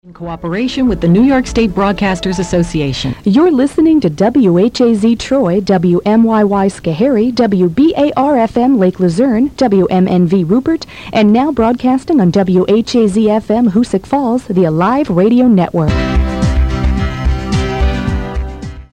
WMYY Top of the Hour Audio: